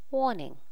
warning.wav